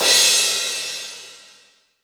Crashes & Cymbals